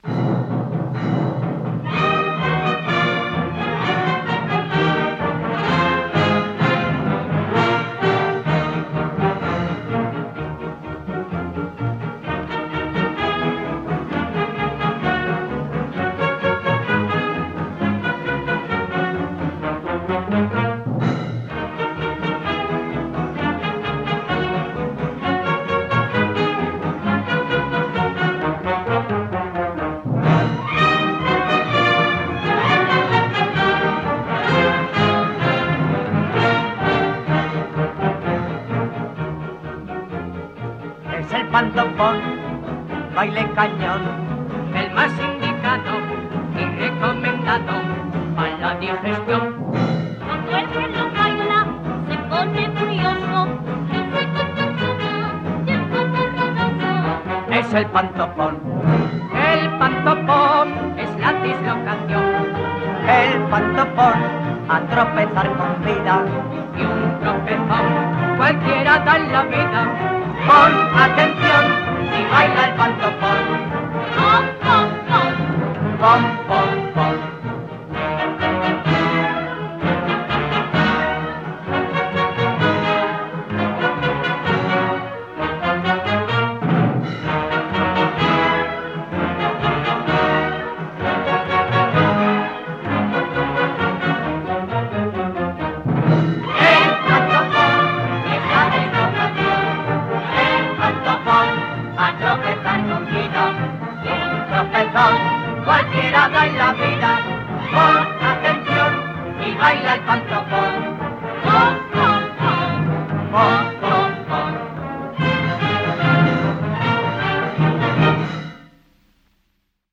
baile original
orquesta
78 rpm.